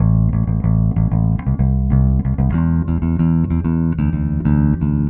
Trem Trance Bass 03a.wav